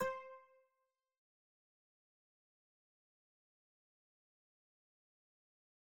cursor_style_3.wav